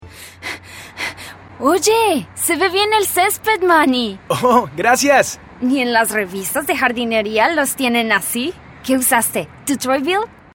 Interpretaciones
Voz versátil y expresiva, hablante nativa de español.
Tono neutro latinoamericano y acentos colombianos.
Tono: Medio (Natural) y Alto-Bajo Opcional.
Acentos: Español colombiano nativo y español neutro LATAM.